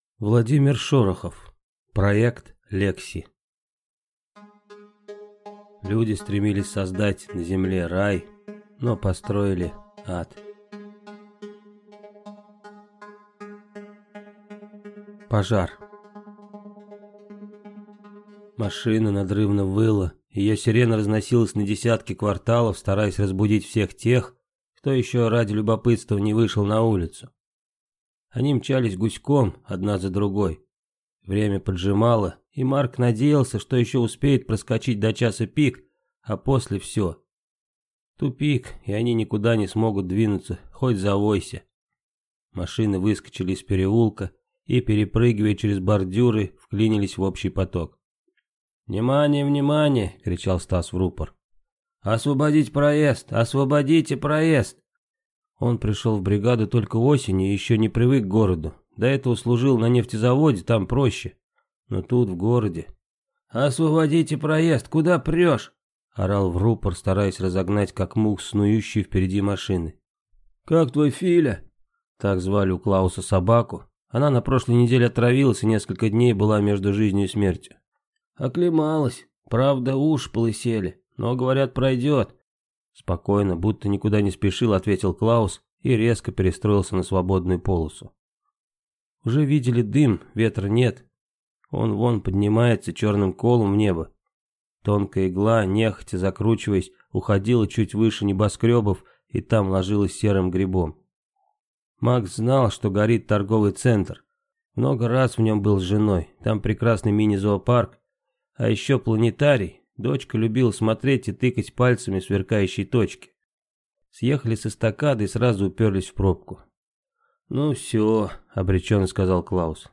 Аудиокнига Проект «Лекси» | Библиотека аудиокниг